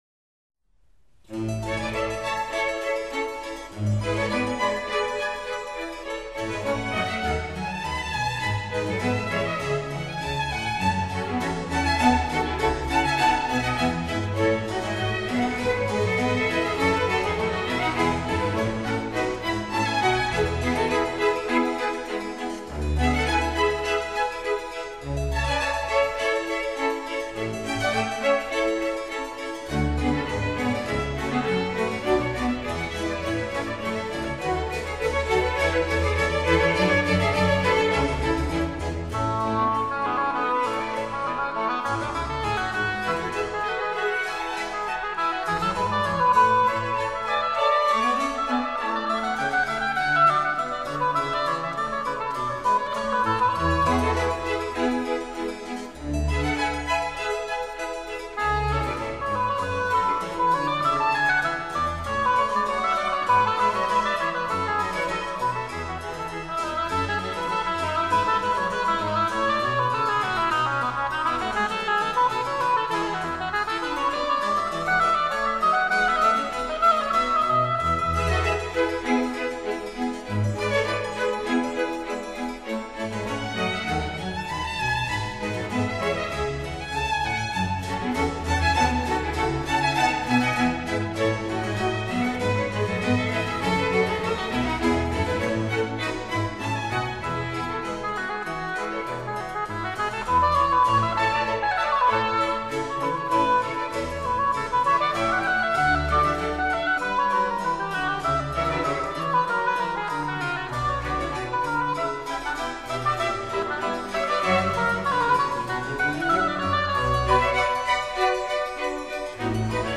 Classical | EAC Rip | FLAC (tracks)+CUE+LOG | | 336 MB.
oboe and oboe d'amore